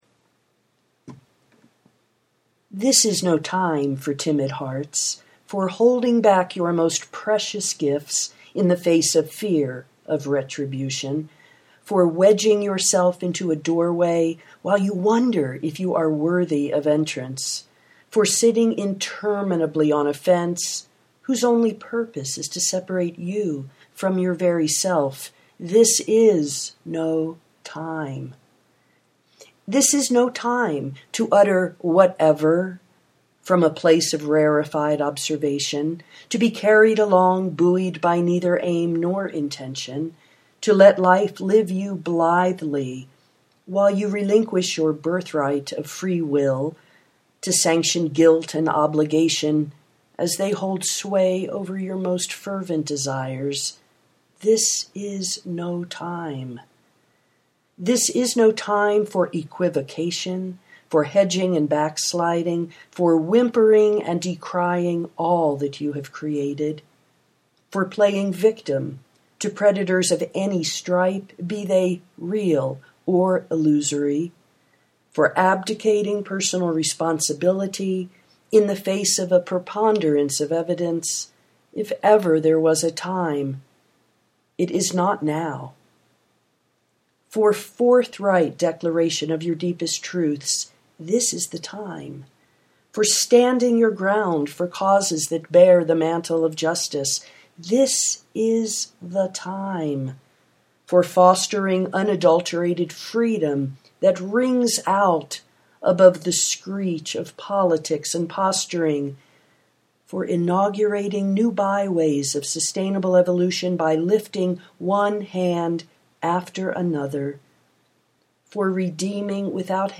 yes, again! this is no time for timid hearts (audio poetry 3:15)
My vibrational frequency has shifted, my voice is different, the larger energies have expanded exponentially — and I suspect the same is true for you.